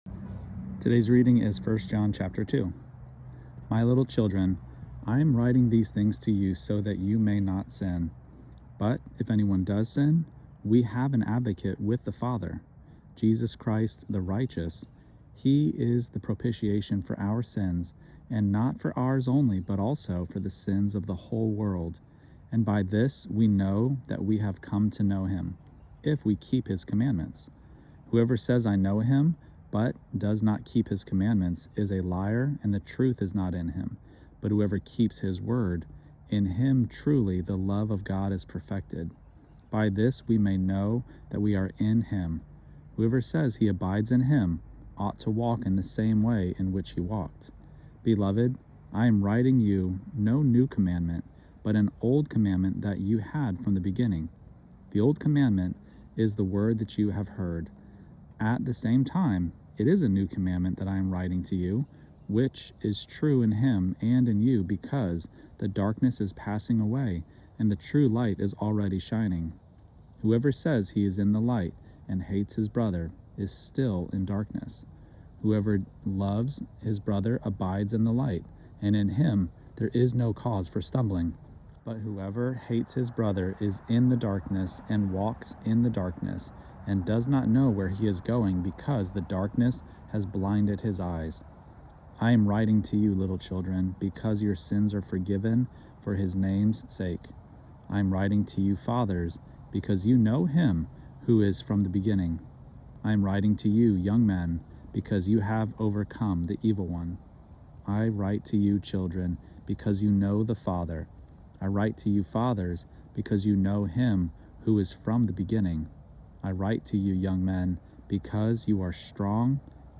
Daily Bible Reading (ESV) December 3: 1 John 2 Play Episode Pause Episode Mute/Unmute Episode Rewind 10 Seconds 1x Fast Forward 30 seconds 00:00 / 4:27 Subscribe Share Apple Podcasts Spotify RSS Feed Share Link Embed